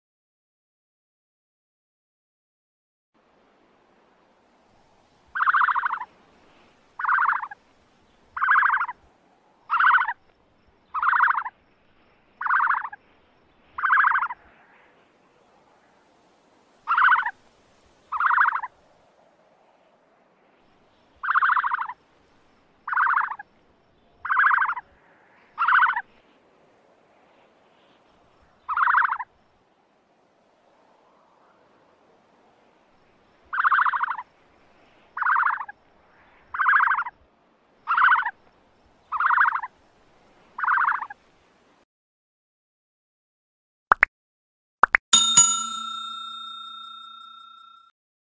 جلوه های صوتی
دانلود صدای شتر مرغ از ساعد نیوز با لینک مستقیم و کیفیت بالا
برچسب: دانلود آهنگ های افکت صوتی انسان و موجودات زنده